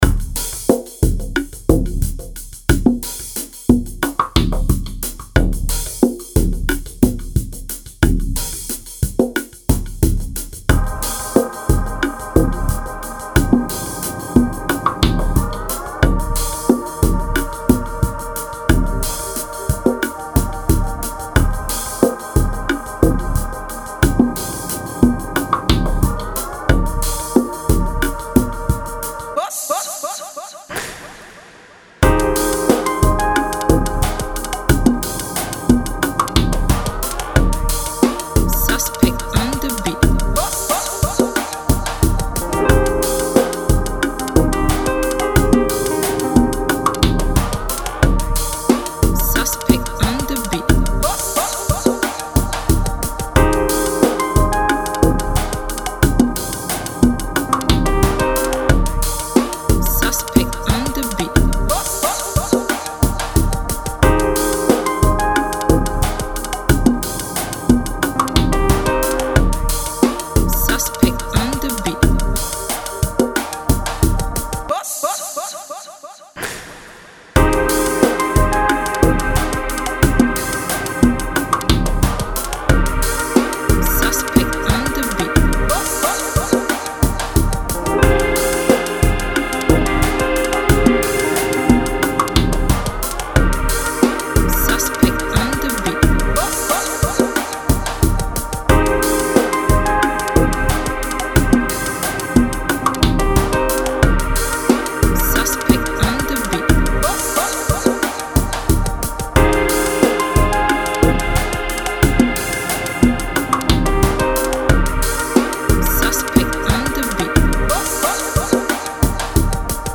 Genre Chillout